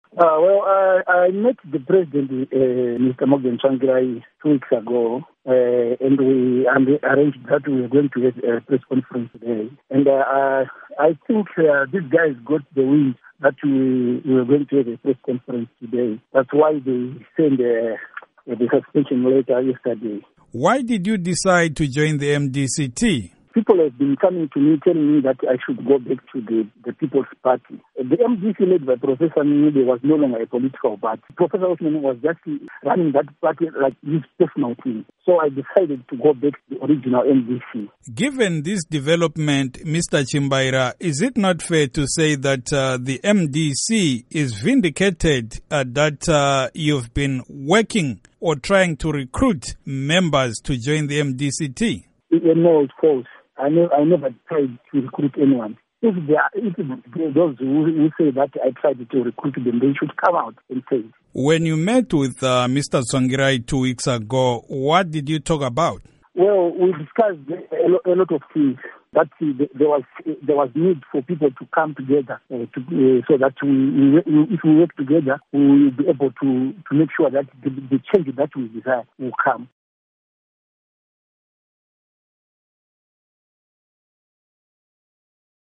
Interview With Goodrich Chimbaira